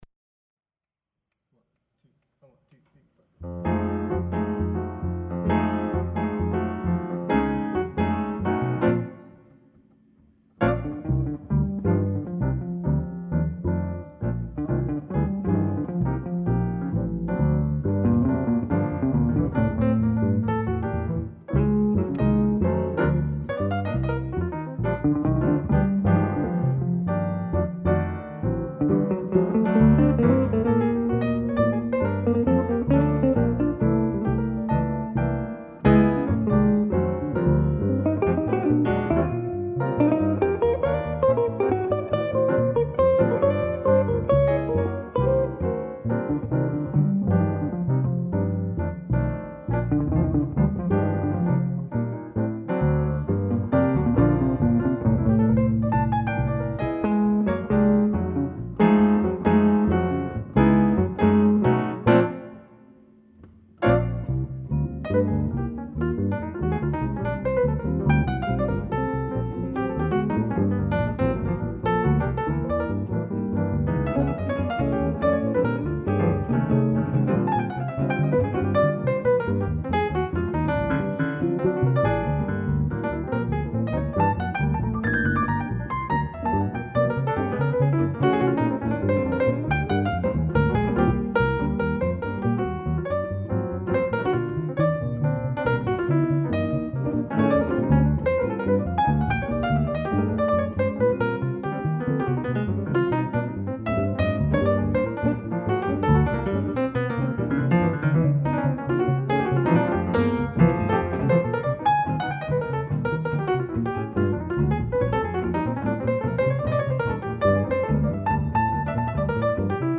Jazz
bass
guitar
piano